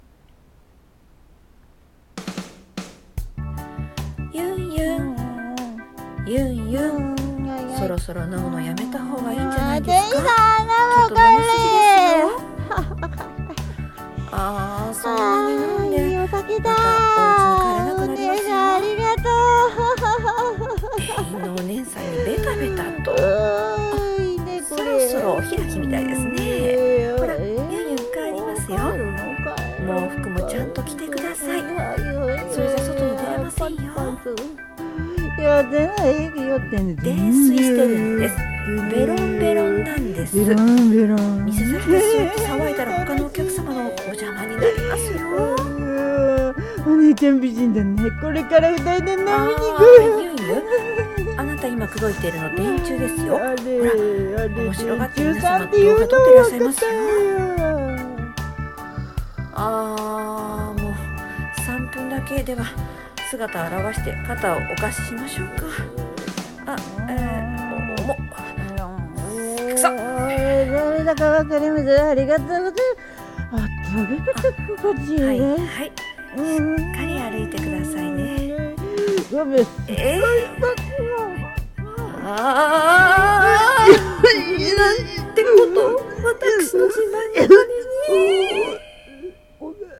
さんの投稿した曲一覧 を表示 2人声劇【ゆんゆんは泥酔】帰るよー💦【天使】